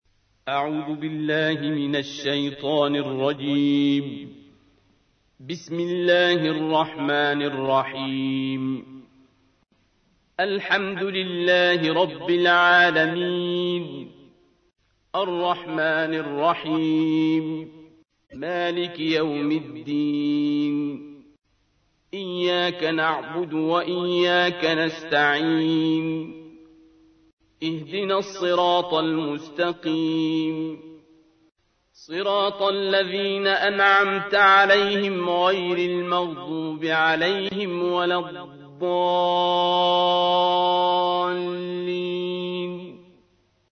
سورة الفاتحة / القارئ عبد الباسط عبد الصمد / القرآن الكريم / موقع يا حسين